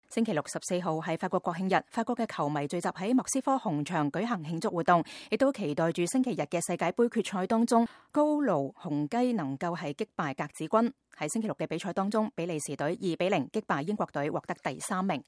14號星期六是法國國慶日（Bastille Day），法國球迷們聚集在莫斯科紅場舉行慶祝活動，也期待著星期天的世界盃決賽中 “高盧雄雞”能夠擊敗“格子軍”。